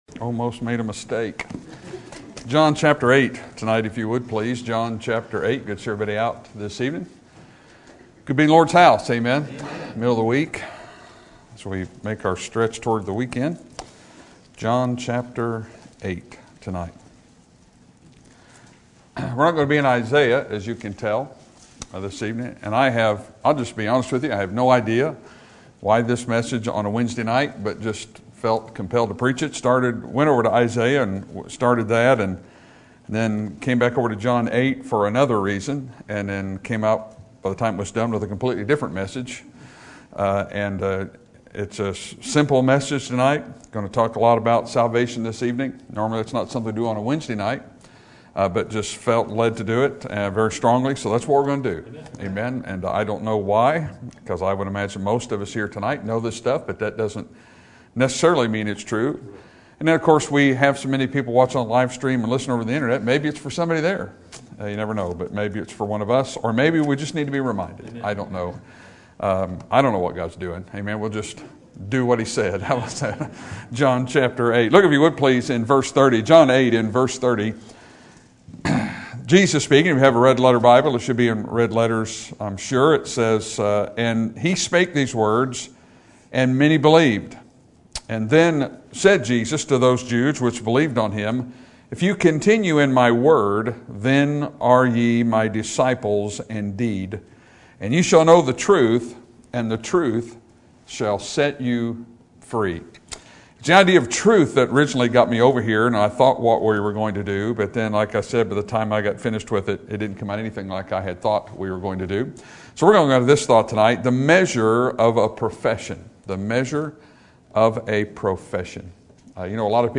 Sermon Topic: General Sermon Type: Service Sermon Audio: Sermon download: Download (32.13 MB) Sermon Tags: John Confession Salvation Obedience